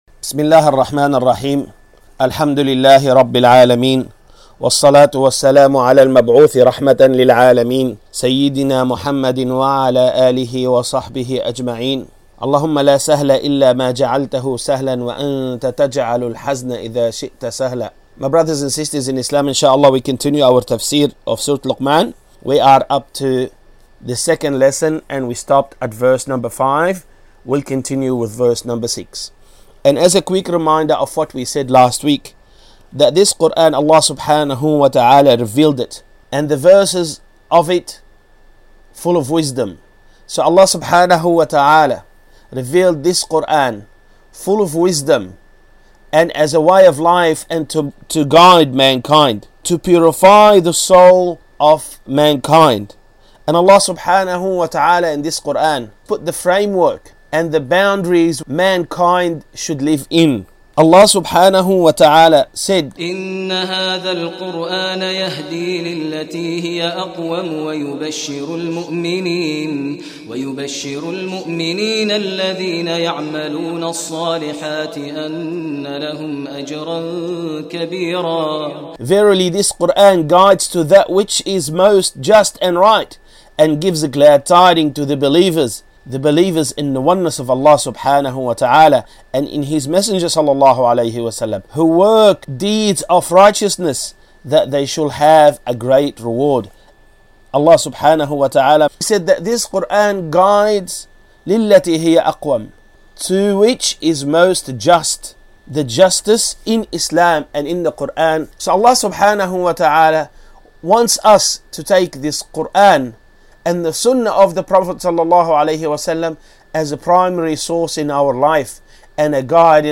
TAFSIR Language
Tafseer_Luqman_2.mp3